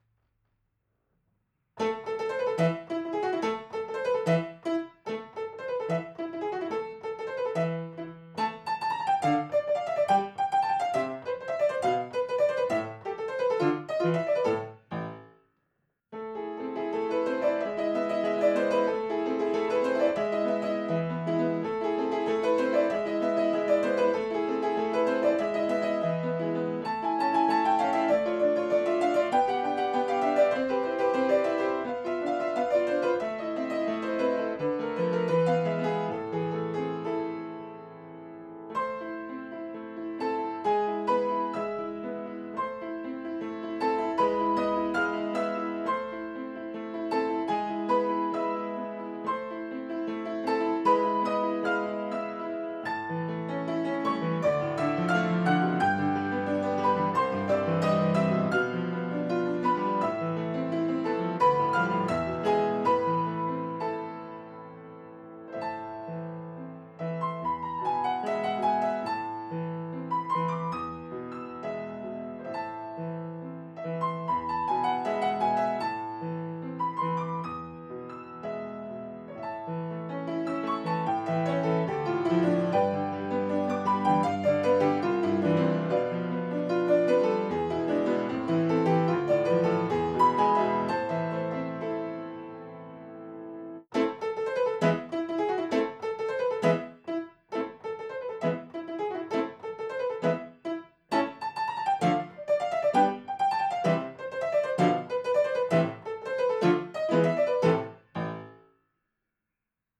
ヴァイオリン・アンサンブルのオススメ：
アンサンブルでは旋律の掛け合いがたまらない